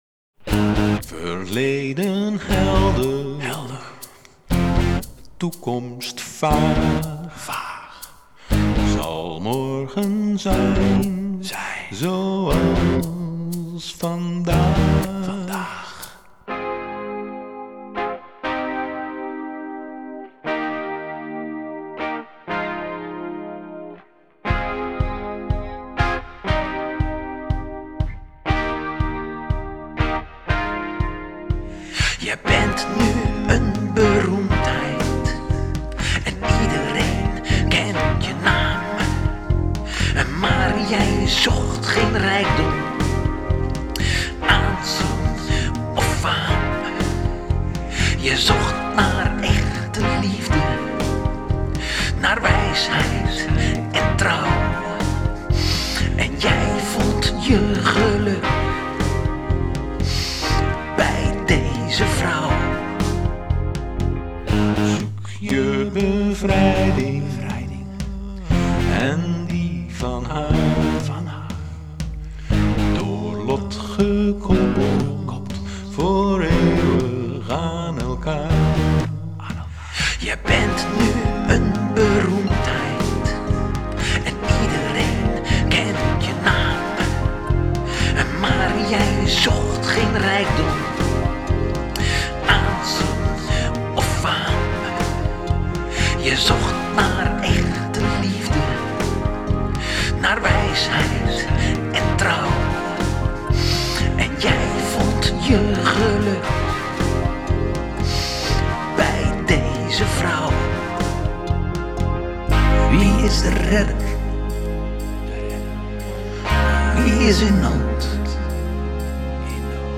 ingespeeld: bas, gitaar, toesten, dummy zang
06_gripir-demo.m4a